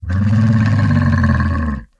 Minecraft Version Minecraft Version 1.21.4 Latest Release | Latest Snapshot 1.21.4 / assets / minecraft / sounds / mob / camel / ambient4.ogg Compare With Compare With Latest Release | Latest Snapshot
ambient4.ogg